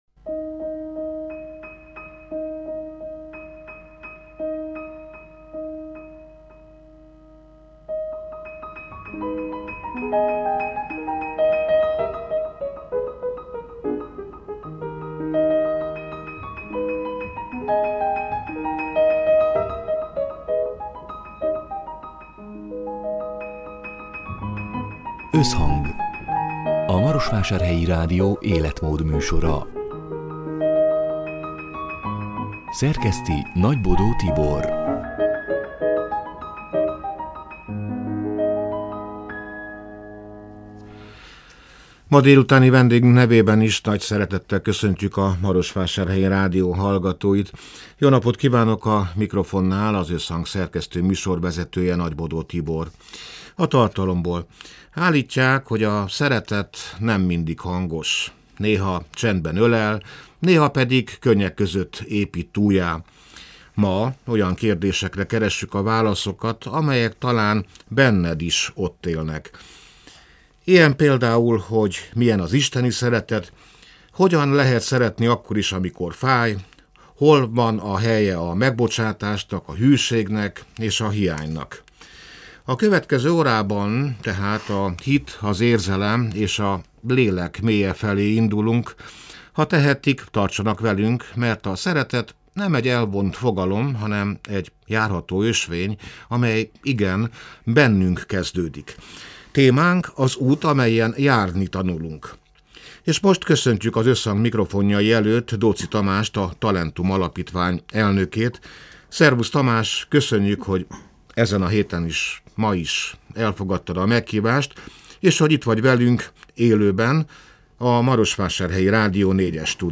(elhangzott: 2025. július 16-án, szerdán délután hat órától élőben)